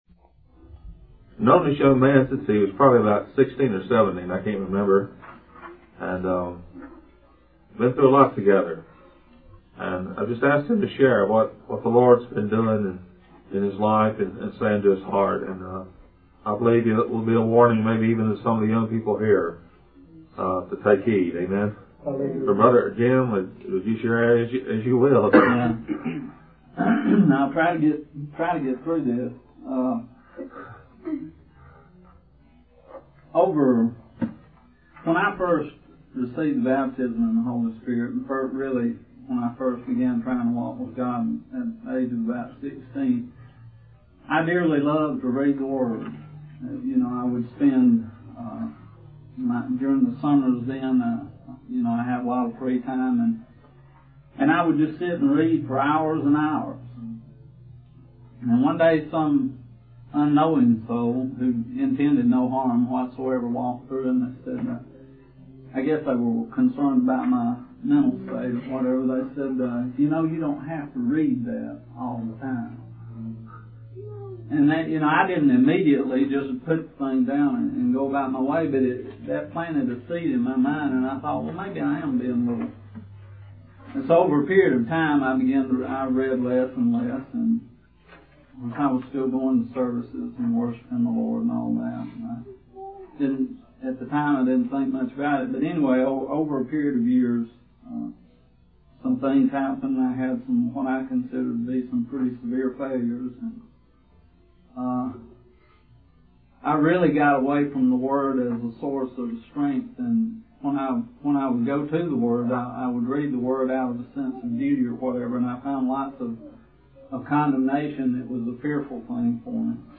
In this sermon, the speaker expresses deep concern for the horrors happening in the world, particularly the kidnapping of children for prostitution. They passionately pray for the gospel of the kingdom to be spread, opening the eyes of people and setting prisoners free. The speaker emphasizes the importance of the living word of God and the need for balance between the written word and the revelation of the Holy Spirit.